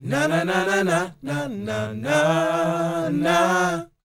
NA-NA E4B -L.wav